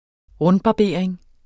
rundbarbering substantiv, fælleskøn Bøjning -en, -er, -erne Udtale Oprindelse kendt fra 1992 Betydninger 1.